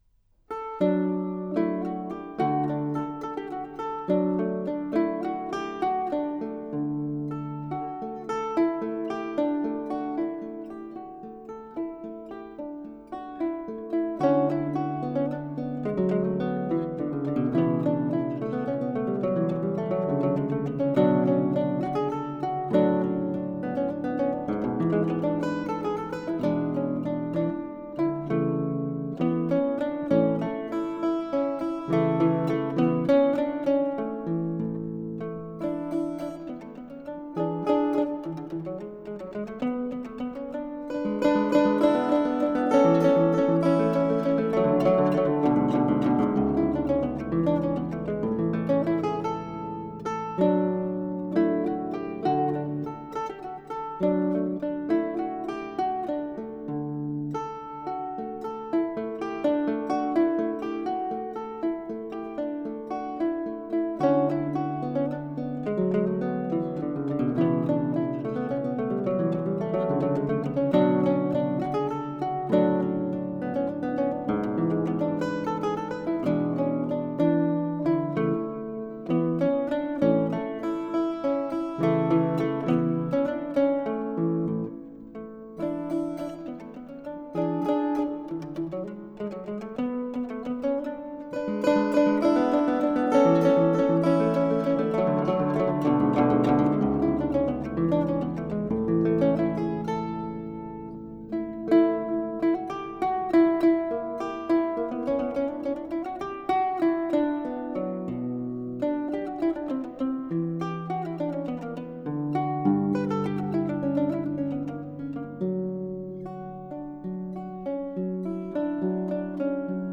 Arr. für Altzither